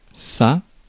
Ääntäminen
Synonyymit bouyei Ääntäminen France: IPA: [sa] Haettu sana löytyi näillä lähdekielillä: ranska Käännös Ääninäyte Substantiivit 1.